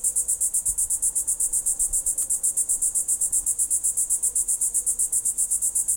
sounds_cicada_01.ogg